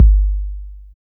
808 THUDK P.wav